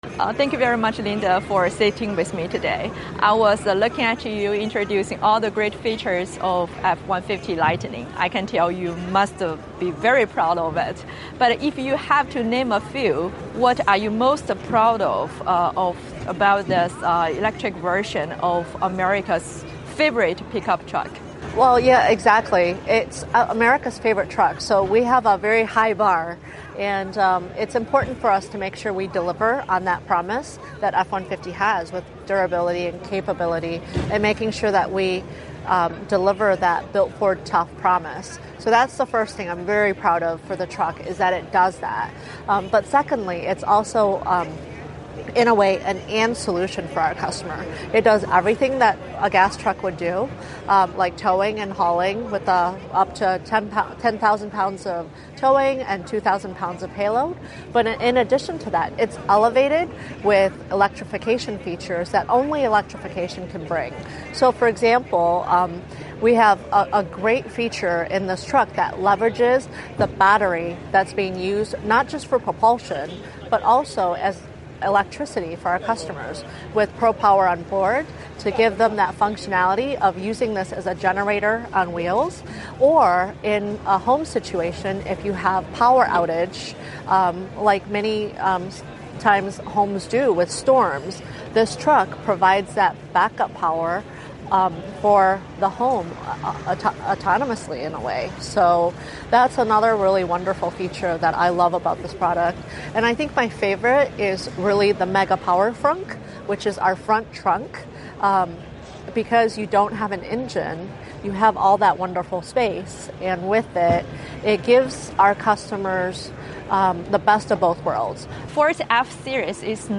日前她在纽约车展上接受了我的专访，谈及电动车的进步以及美中在电动车领域的竞争。